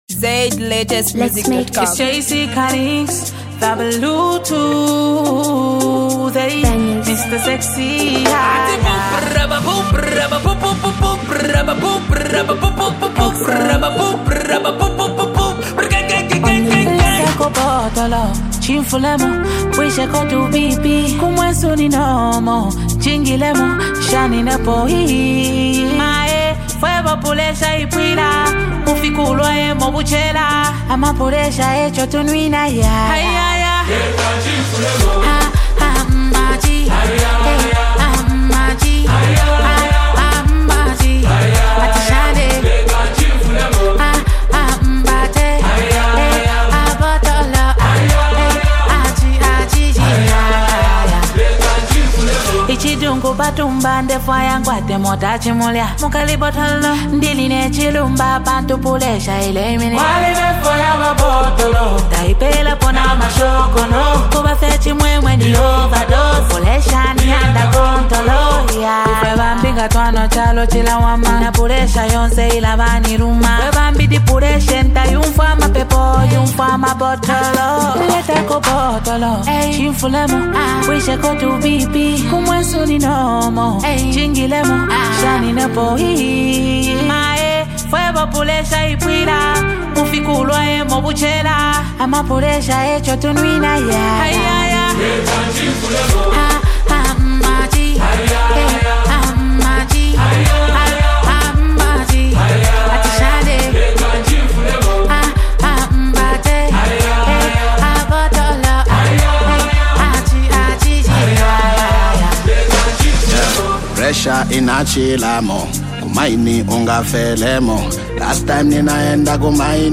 captures the vibrant energy
merging two distinct styles into one harmonious hit.
Genre: Zambia Songs